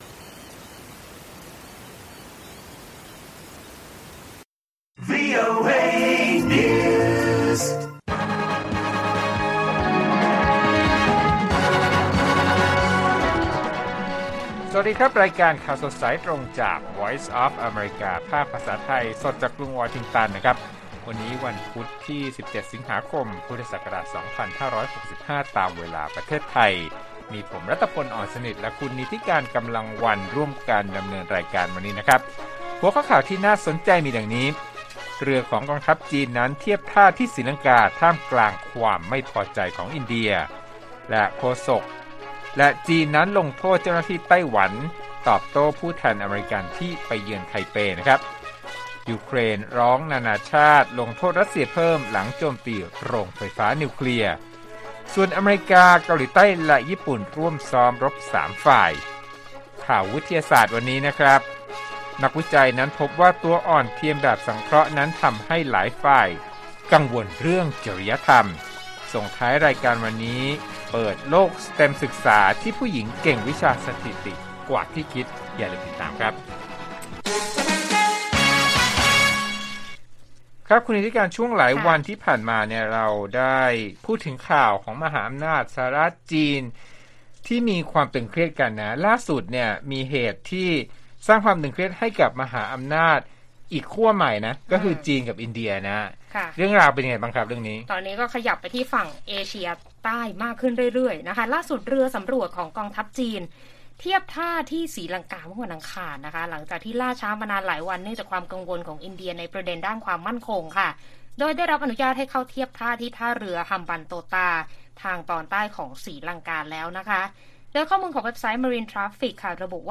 ข่าวสดสายตรงจากวีโอเอไทย 6:30 – 7:00 น. วันที่ 17 ส.ค. 65